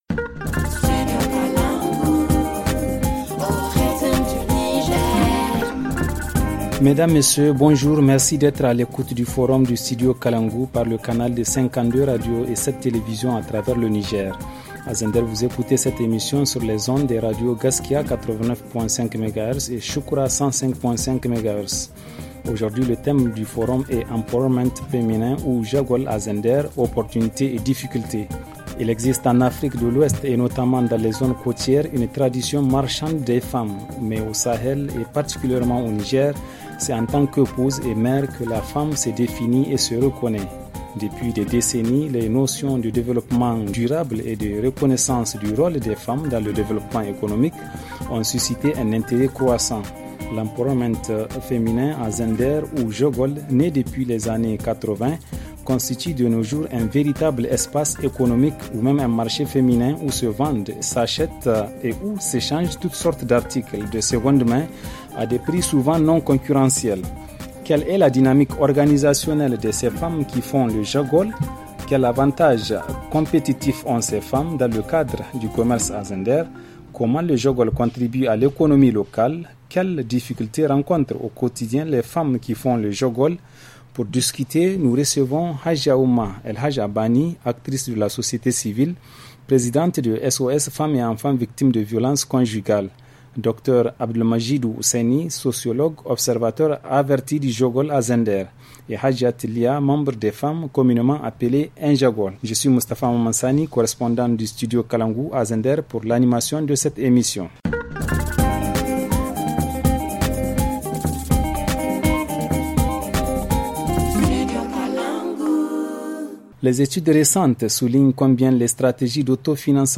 Le forum en français